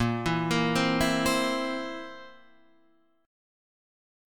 A#sus2b5 chord {6 7 8 5 5 8} chord